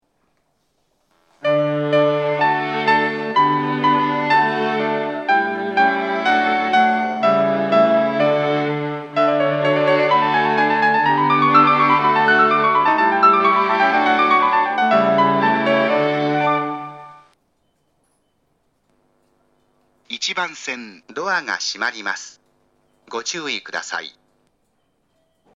発車メロディーは上下とも同じ曲が流れます。
発車メロディー
余韻切りです。遅れていなければ余韻まで鳴りやすいです。